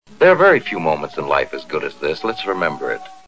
The actor was remembered by staff as "a thick-spectacled, mustached, long-haired lover of horseplay, whose resonant voice and flawless diction seemed at first, an affectation."
Marlowe also has a way of speaking with clear and precise enunciation of words so that the audience has no trouble hearing and understanding what he is saying.